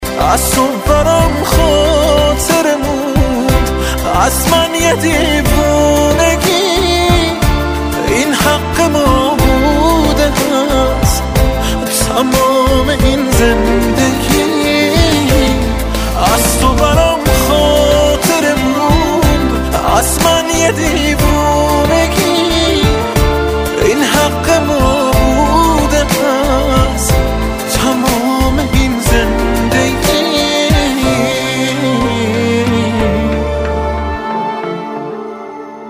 زنگ خور رمانتیک و احساسی موبایل (با کلام)